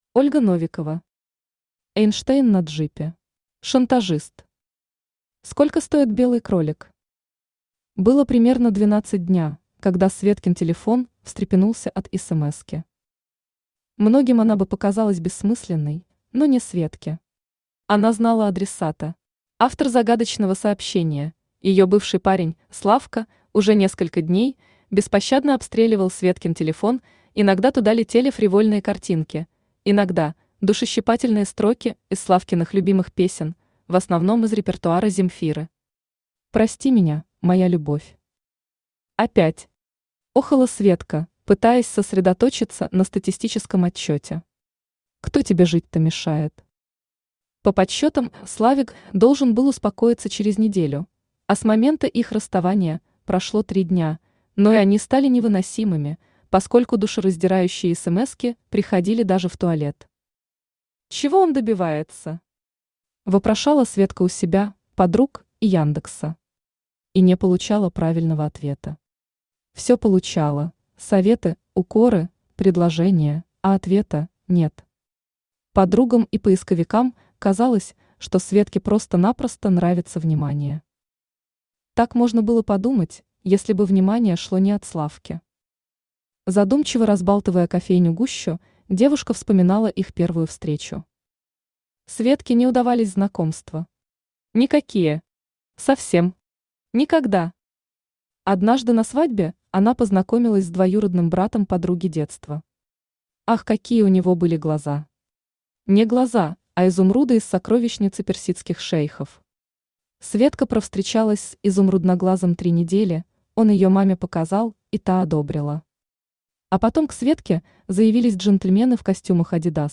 Аудиокнига Эйнштейн на джипе | Библиотека аудиокниг
Aудиокнига Эйнштейн на джипе Автор Ольга Викторовна Новикова Читает аудиокнигу Авточтец ЛитРес.